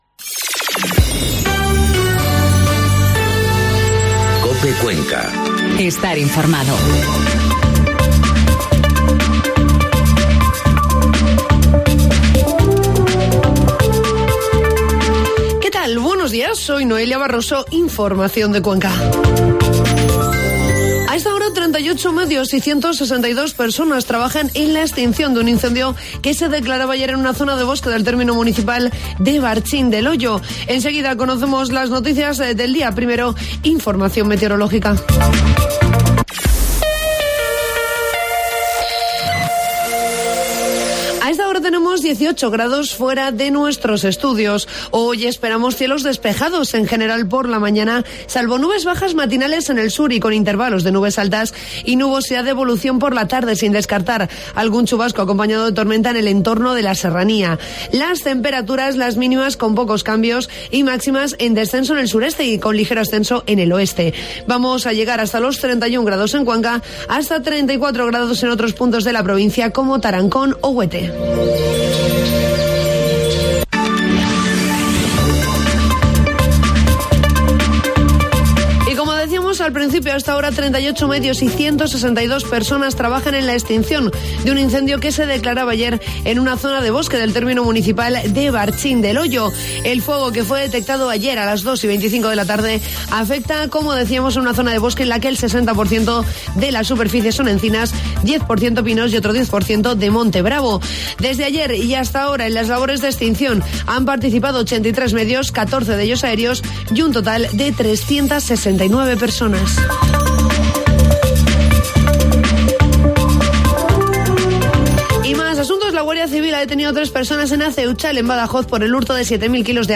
Informativo matinal COPE Cuenca 31 de julio